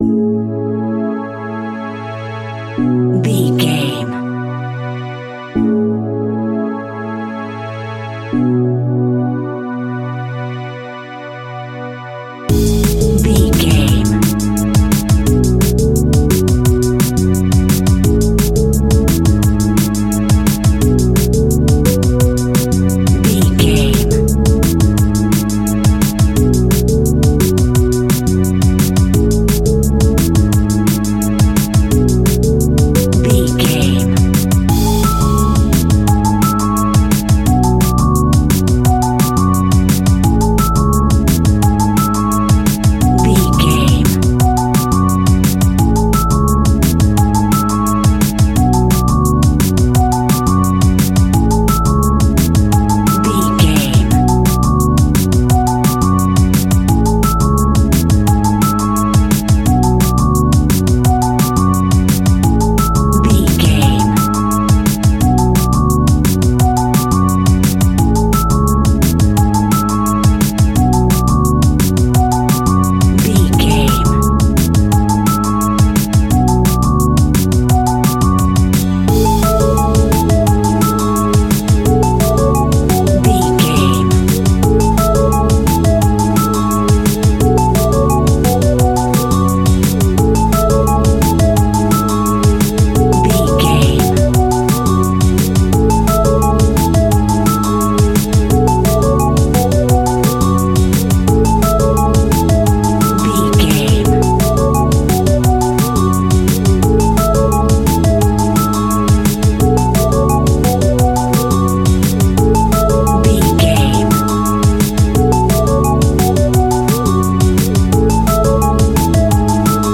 Ionian/Major
Fast
groovy
uplifting
futuristic
driving
energetic
repetitive
drum machine
synthesiser
Drum and bass
electronic
instrumentals
synth bass
synth lead
synth drums
synth pad
robotic